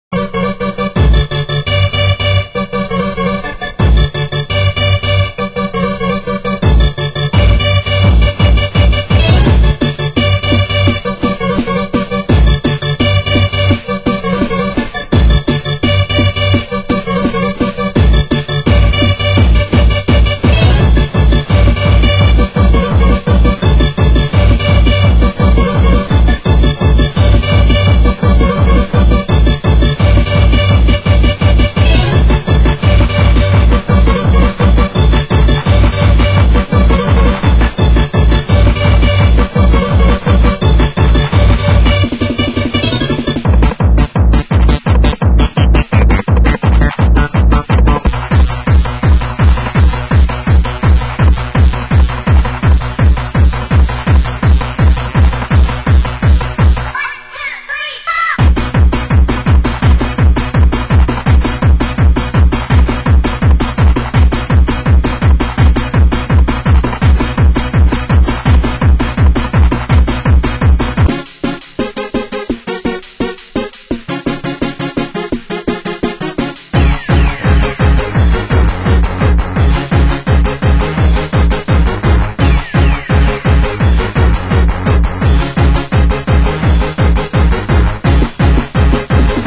Genre Happy Hardcore